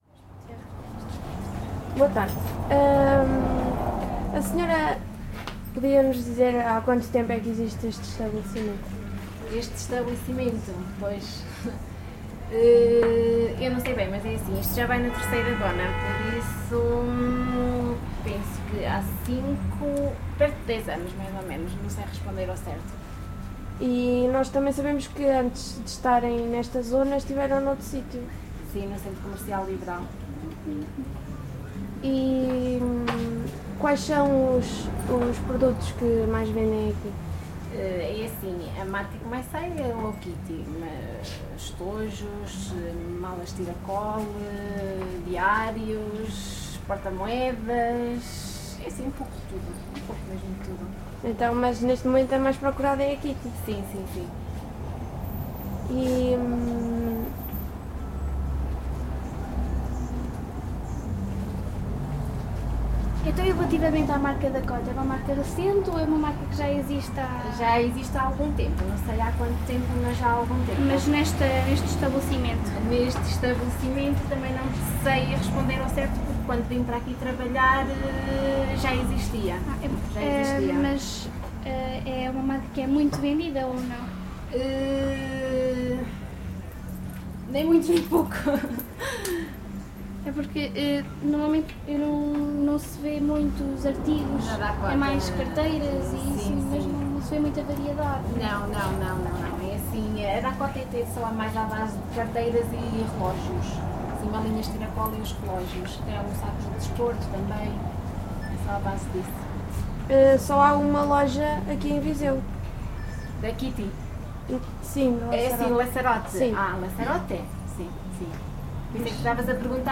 Gravado com Edirol R44 e um par de microfones de lapela Audio-Technica AT899.
Inquérito Etnográfico, Inquérito Oral